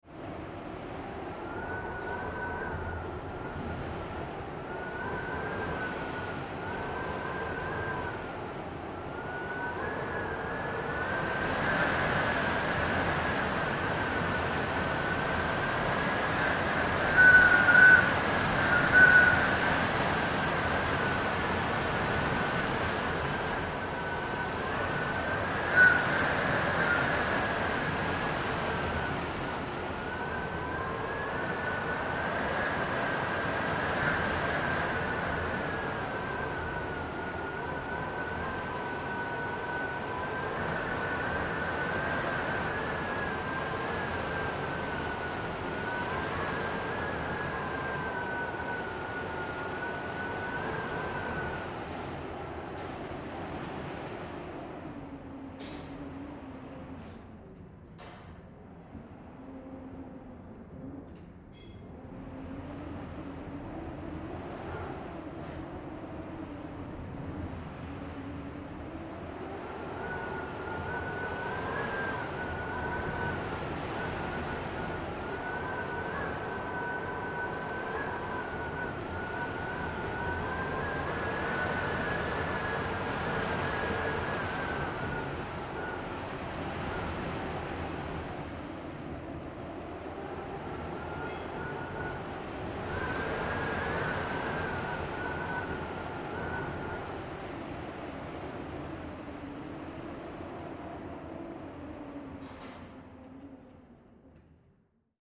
Подборка передает всю мощь природного явления: свист ветра, грохот падающих предметов, тревожную атмосферу.
Шум ветра в разгар урагана